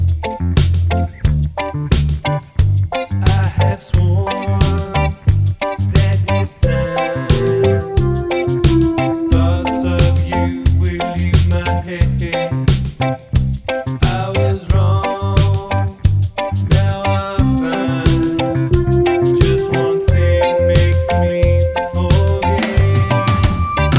One Semitone Down Reggae 3:08 Buy £1.50